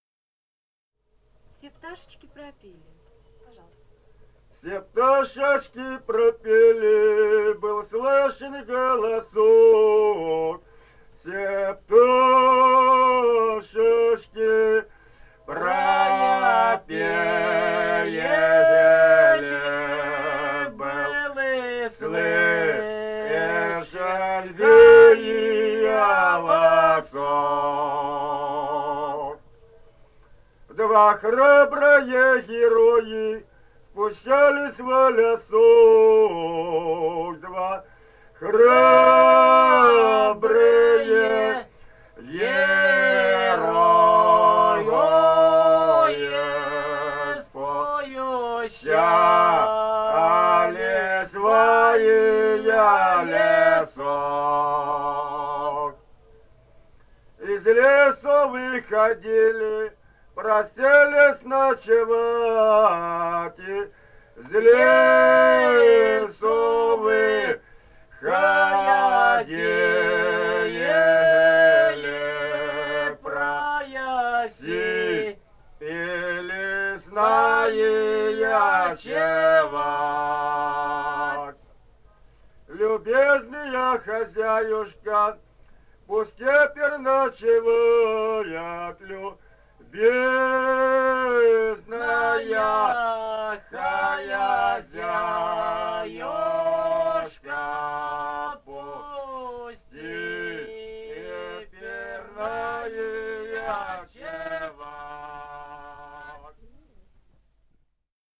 Этномузыкологические исследования и полевые материалы
«Все пташечки пропели» (лирическая).
Алтайский край, с. Маралиха Чарышского района, 1967 г. И1002-02